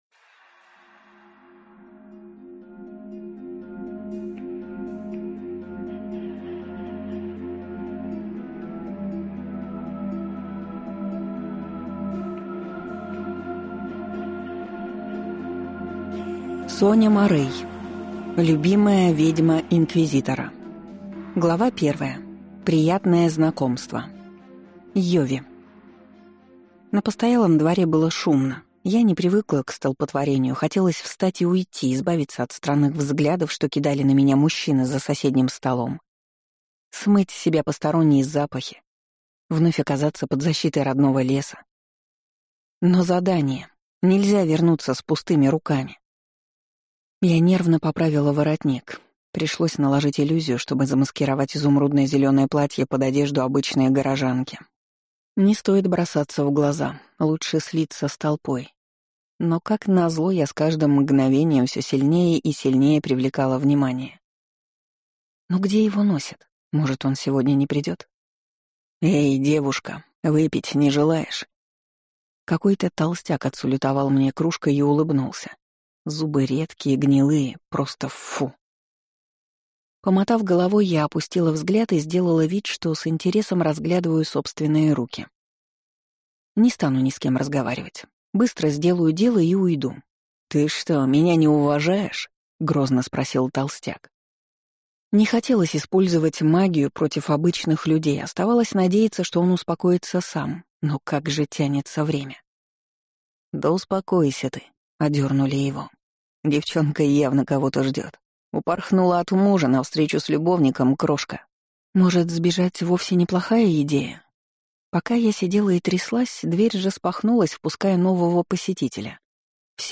Аудиокнига Любимая ведьма инквизитора | Библиотека аудиокниг